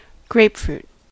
grapefruit: Wikimedia Commons US English Pronunciations
En-us-grapefruit.WAV